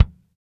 KIK JAZZ D0C.wav